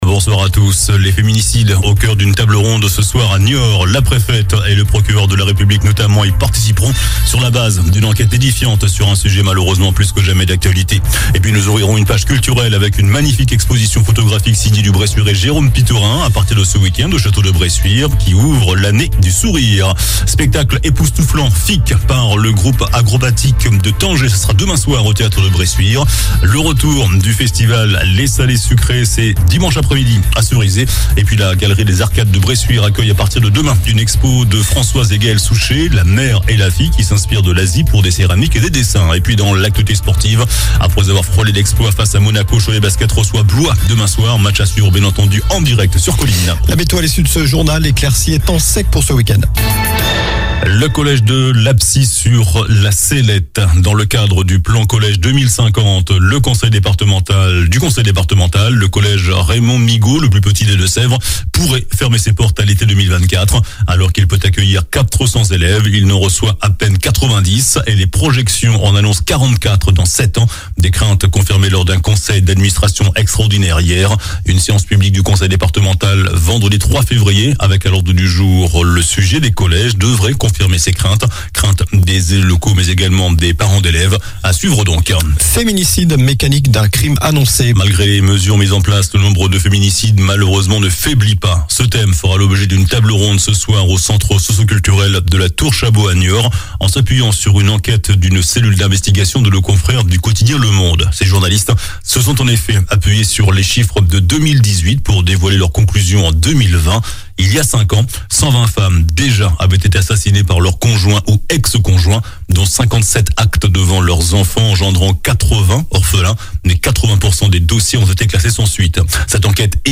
JOURNAL DU VENDREDI 20 JANVIER ( SOIR )